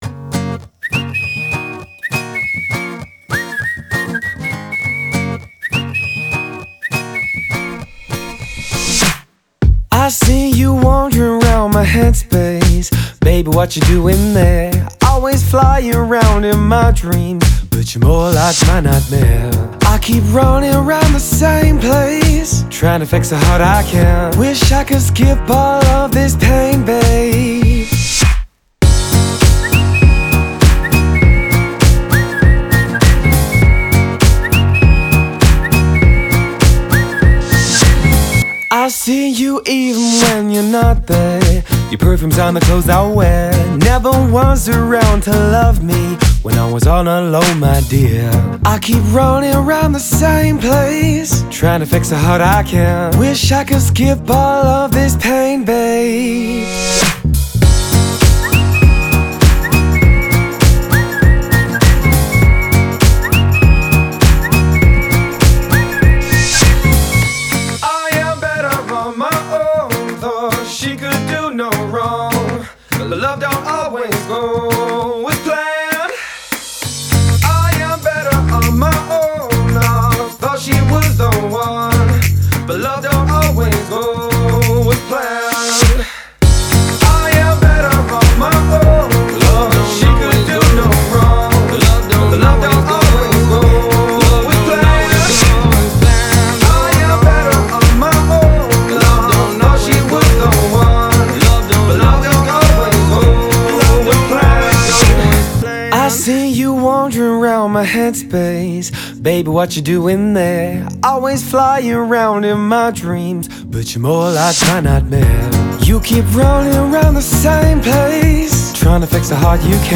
это мелодичная композиция в жанре электронной музыки